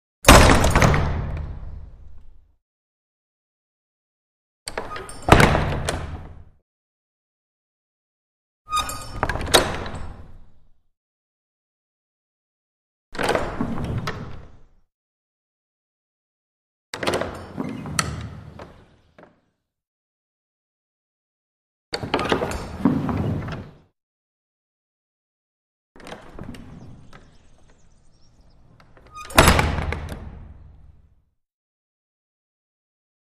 Large Wooden Main Gate With Bell; Opens And Closes, Exterior / Interior Arched Stone Entrance Way